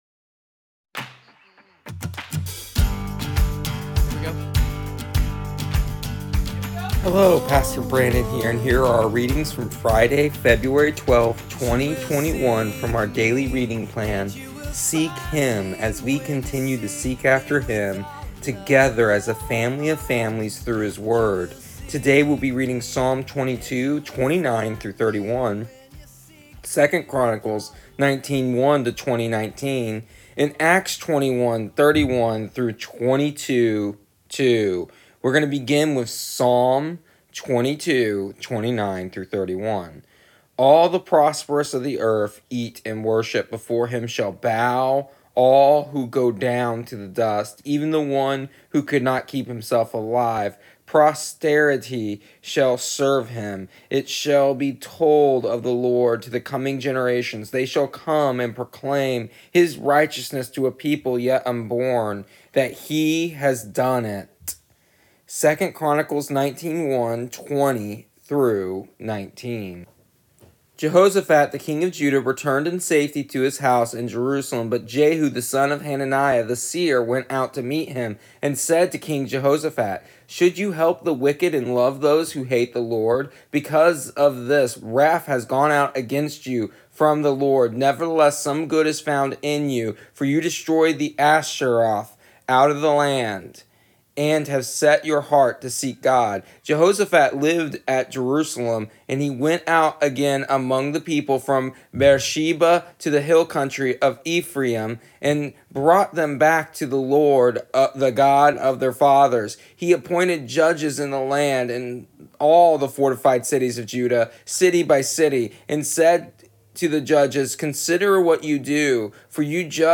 Here is the audio version of our daily readings from our daily reading plan Seek Him for February 12th, 2021.